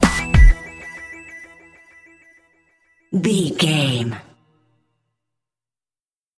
Aeolian/Minor
drums
electric piano
strings